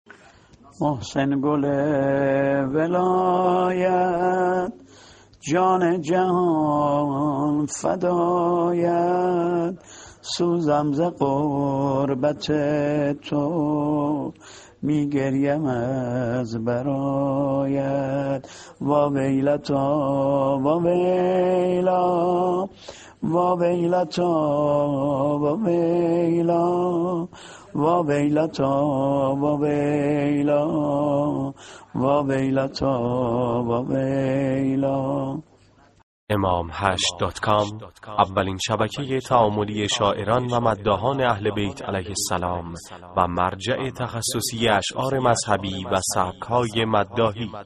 متن شعر و نوحه حضرت محسن علیه السلام -( محسن گل ولایت جان جهان فدایت)